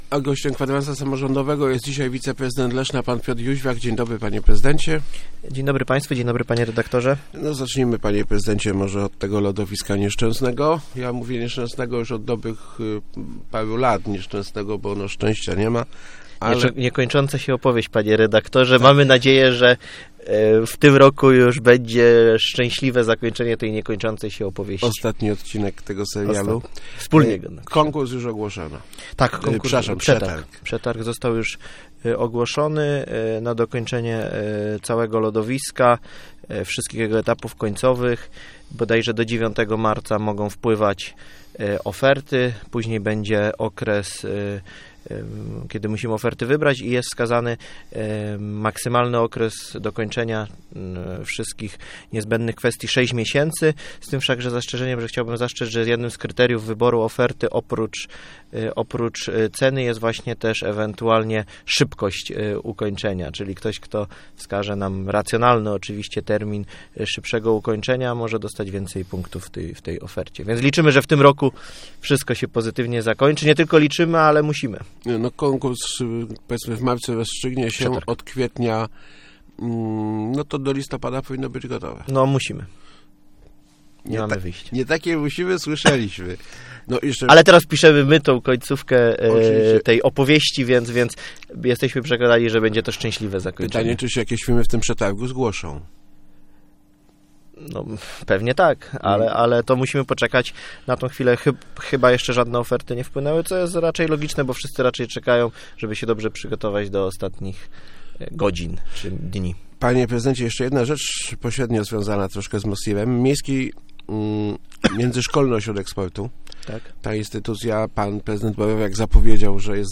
Gościem Kwadransa był wiceprezydent Piotr Jóźwiak ...